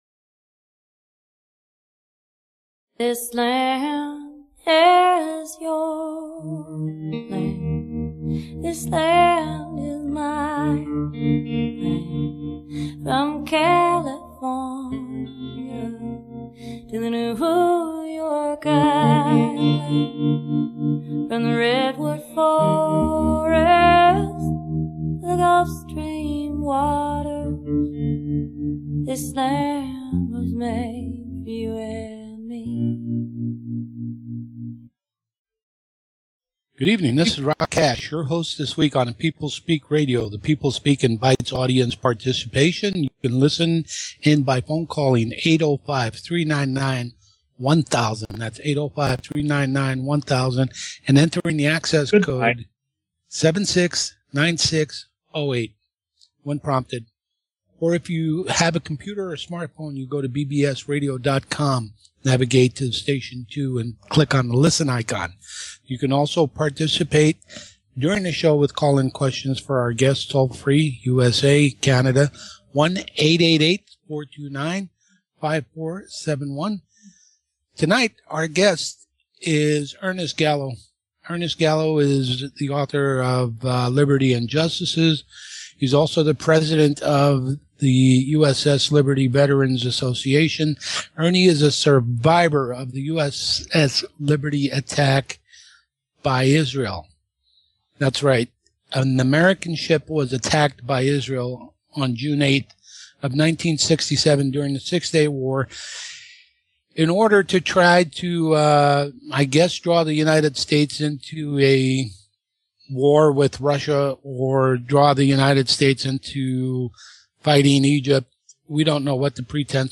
The People Speak has evolved over the years with many great guests who have been interviewed by some very fine hosts.